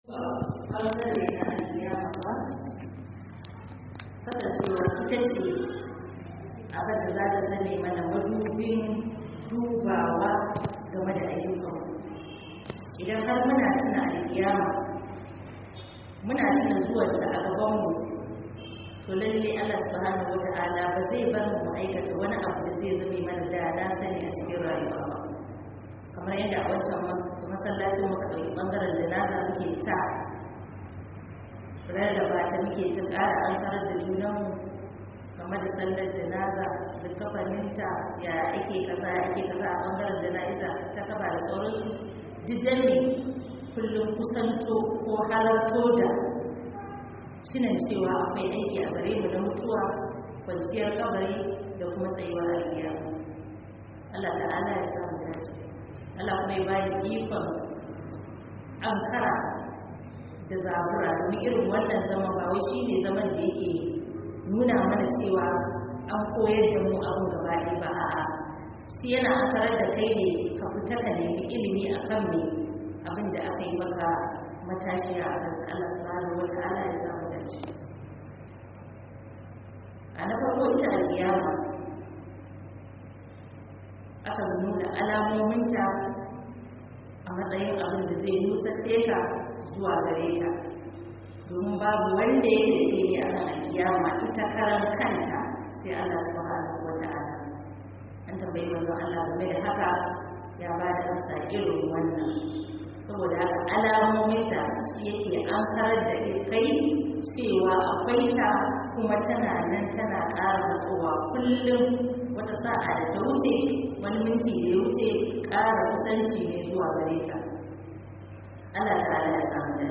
Book Muhadara